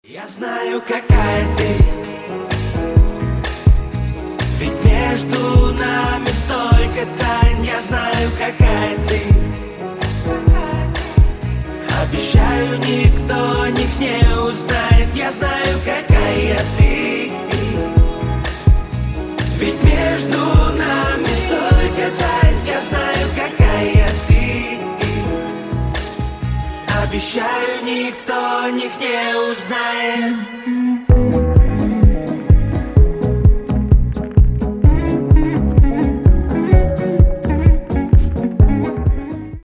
Рэп Хип-Хоп Рингтоны